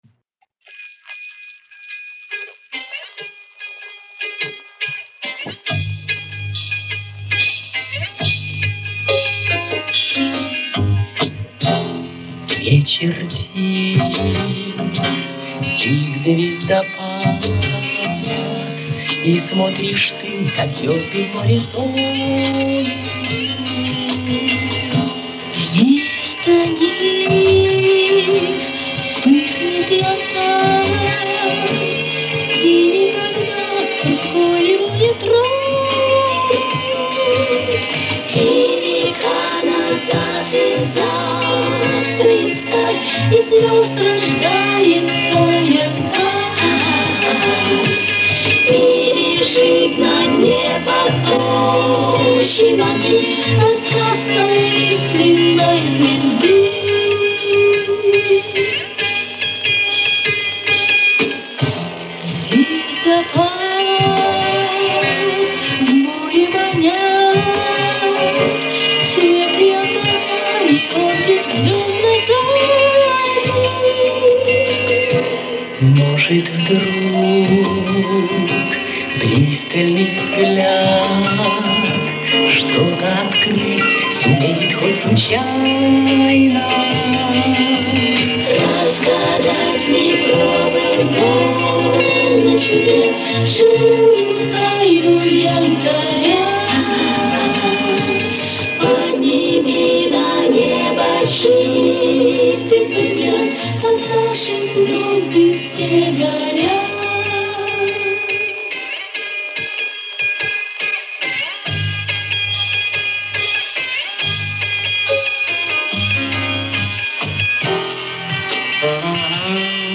Качество, какое есть...